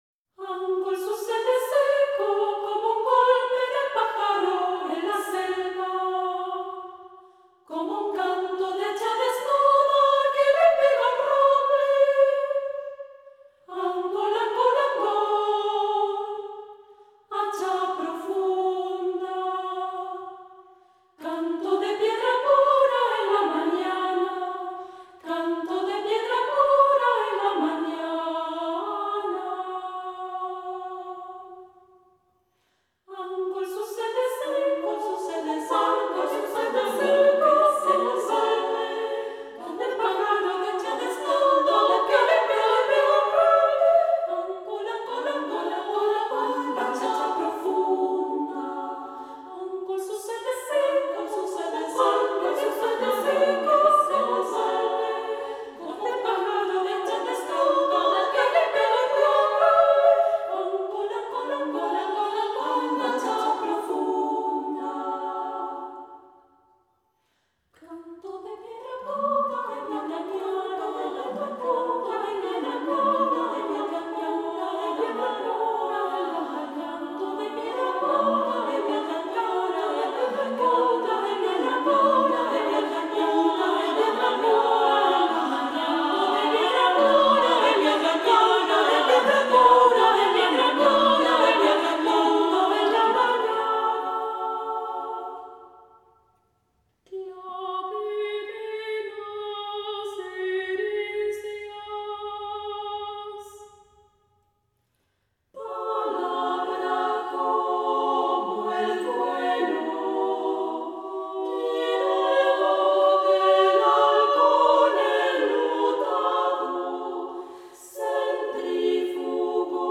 Angol para coro